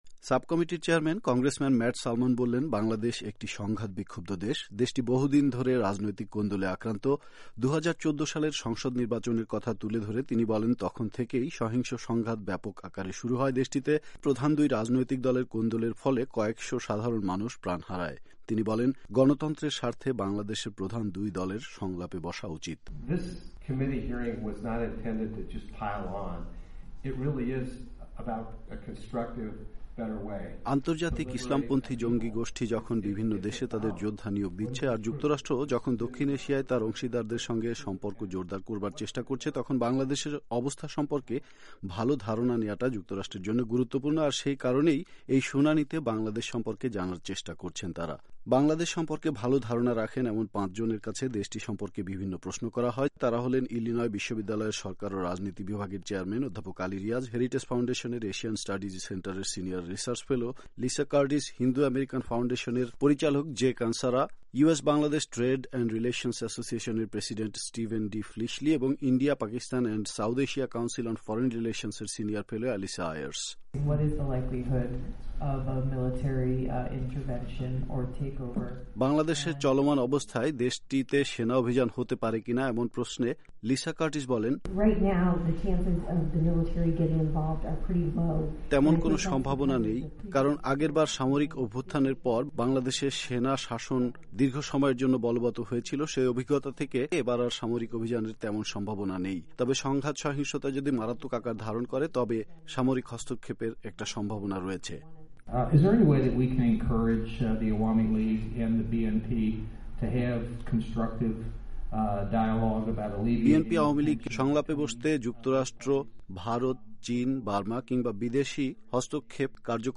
বাংলাদেশকে যুক্তরাষ্ট্র কিভাবে সহায়তা করতে পারে সে বিষয়ে কংগ্রেসের শুনানি অনুষ্ঠিত হলো ওয়াশিংটনে